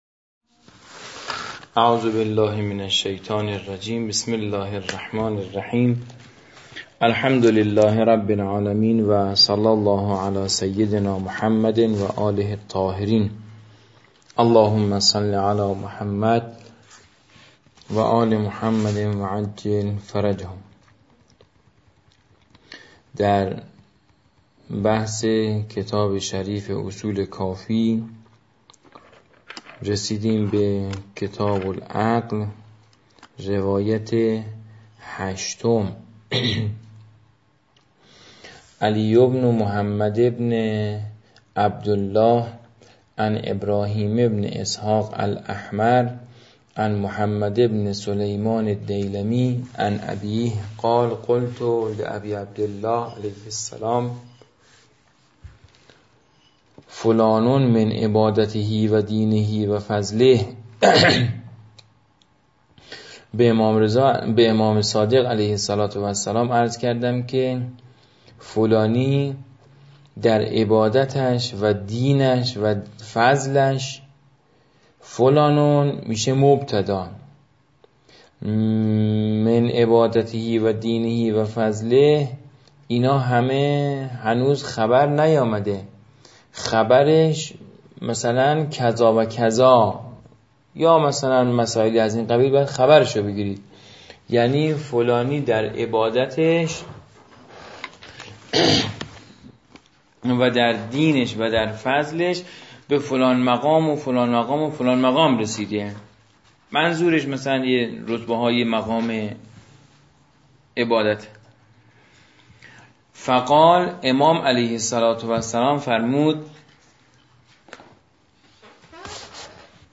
مدت این درس: 26 دقیقه